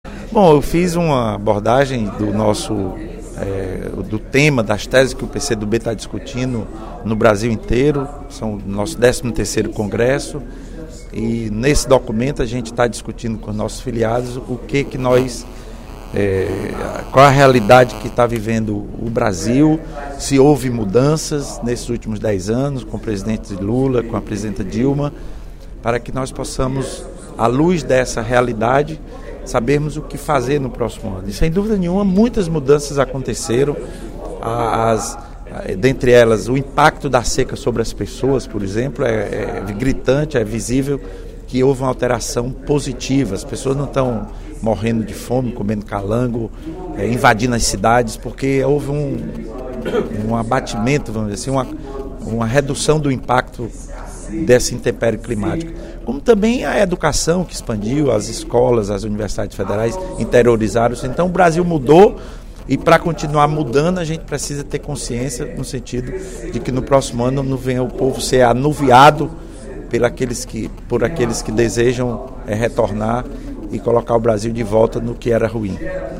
Durante o primeiro expediente da sessão plenária desta sexta-feira (06/09), o deputado Lula Morais (PCdoB) destacou as mudanças no enfrentamento dos efeitos da seca no Nordeste, especialmente no Ceará, nos últimos anos.